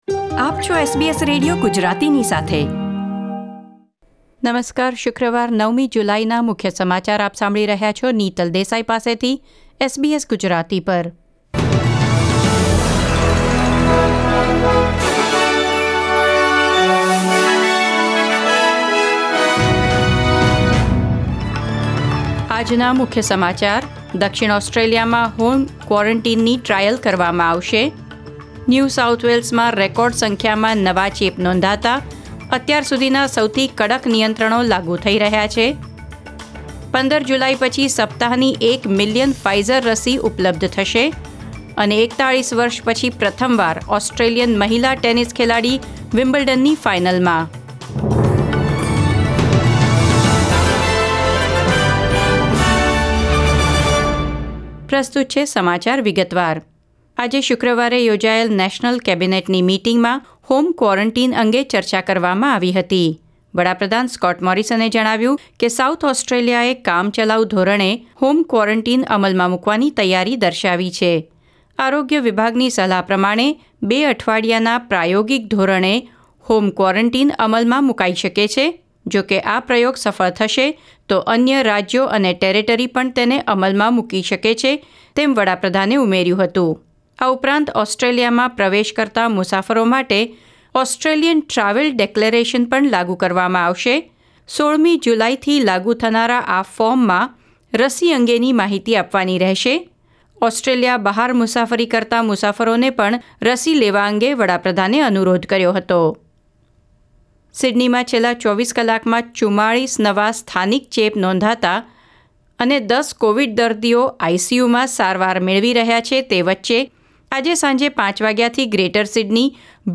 SBS Gujarati News Bulletin 9 July 2021